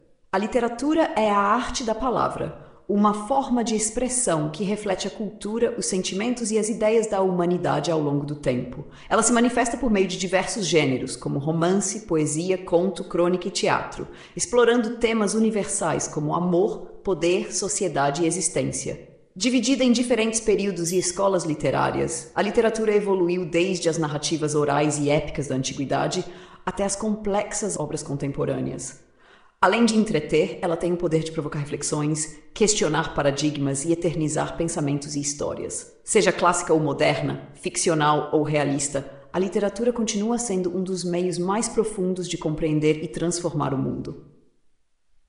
Intellecta-Resumo-sobre-literatura-com-reverber.mp3